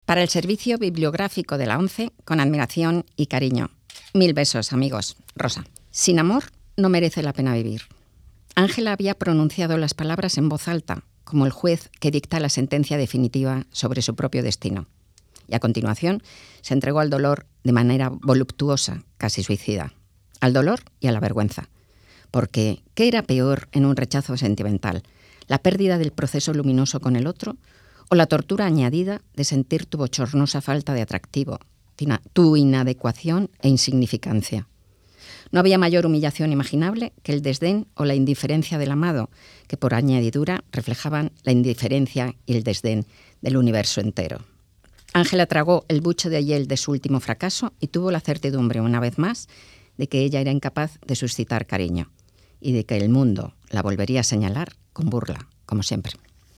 Sirvió como 'madrina' del acto la popular periodista y escritora Rosa Montero que, aprovechando la ocasión,
grabó el arranque formato MP3 audio(1,08 MB) de uno de sus textos, "Los tiempos del odio", que se incorporará así al fondo de audiolibros para personas ciegas.